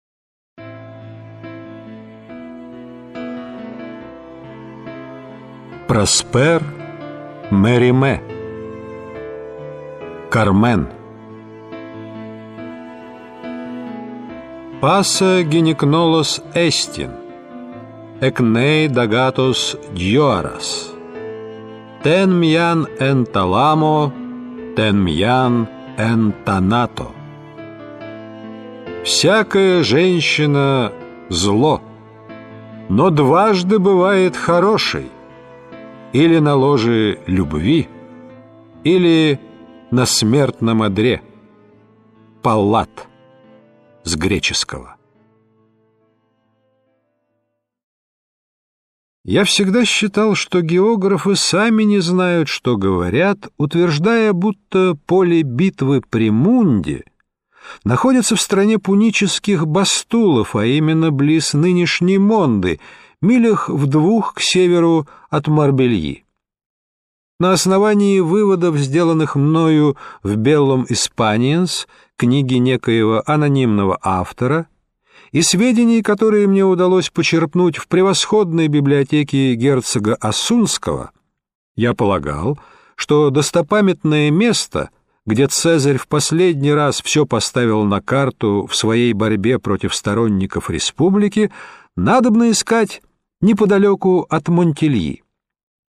Аудиокниги